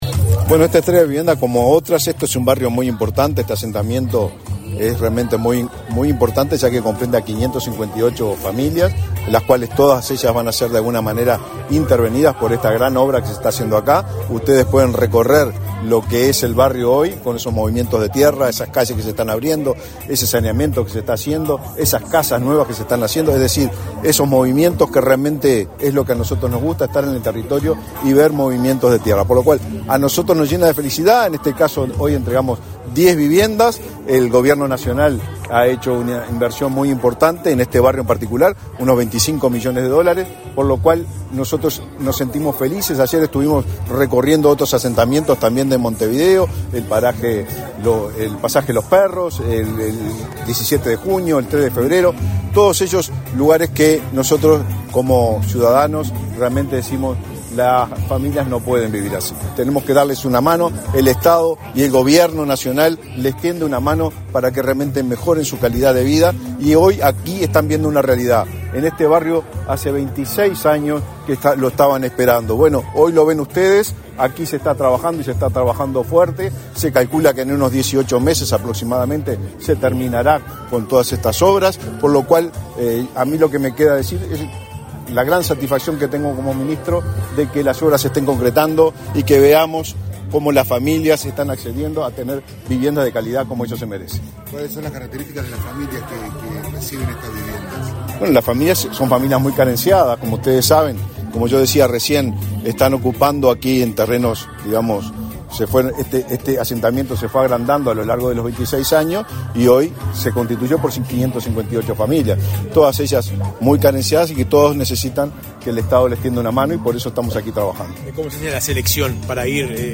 Declaraciones a la prensa del ministro del MVOT, Raúl Lozano